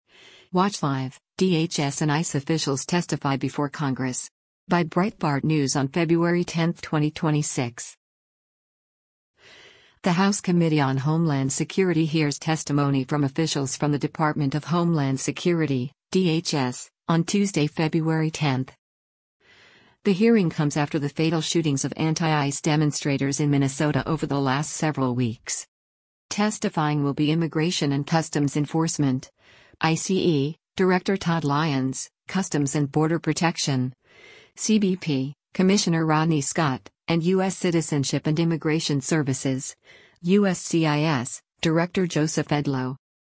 The House Committee on Homeland Security hears testimony from officials from the Department of Homeland Security (DHS) on Tuesday, February 10.
Testifying will be Immigration and Customs Enforcement (ICE) Director Todd Lyons, Customs and Border Protection (CBP) Commissioner Rodney Scott, and U.S. Citizenship and Immigration Services (USCIS) Director Joseph Edlow.